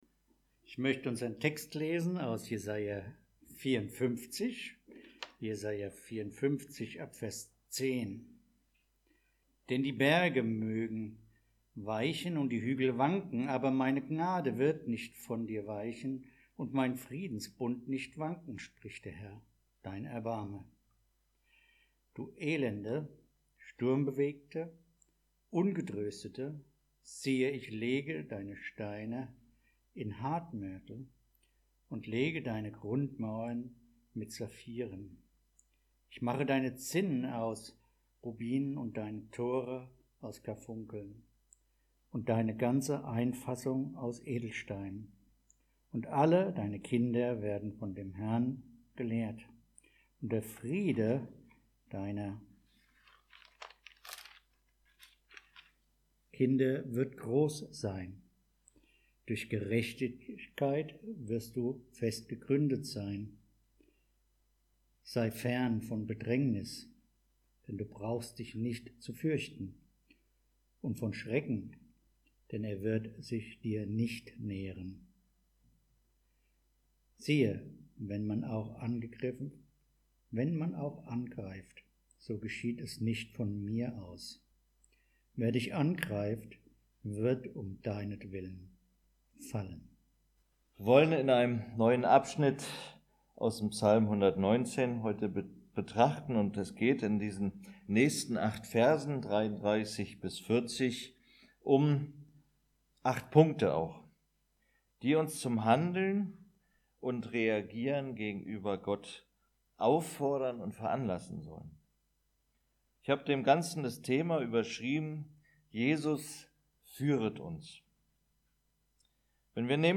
Audio Predigten | Christusgemeinde Frankfurt | Seite 3